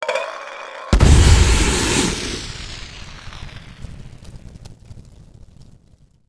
smokegrenade.wav